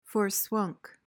PRONUNCIATION: (for-SWUNK) MEANING: adjective: Exhausted.